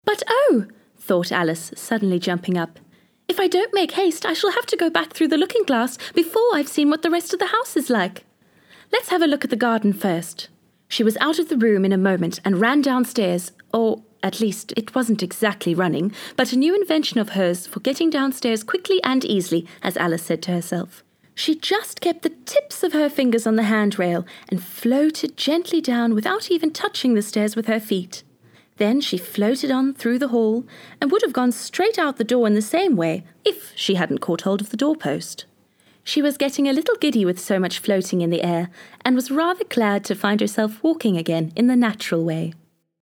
Audiobooks
I bring color and imagery, coupled with technique and consistency.